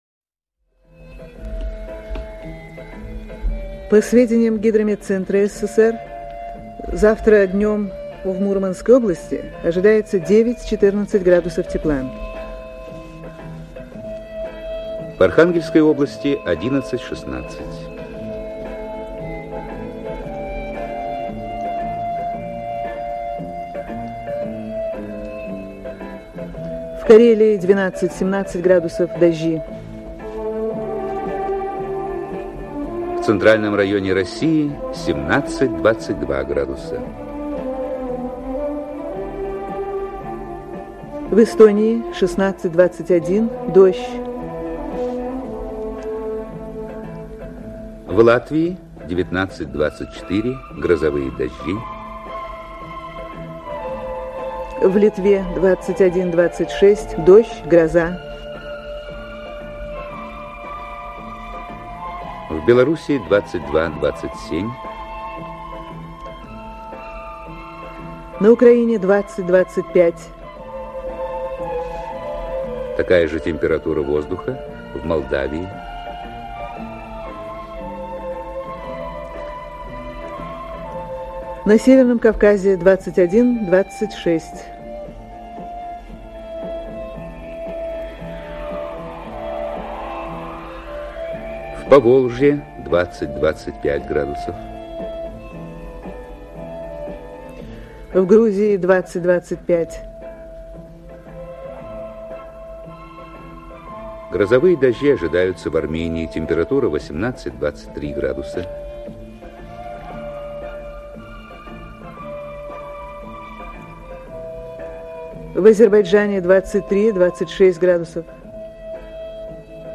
Фрагмент программы Время. Два диктора.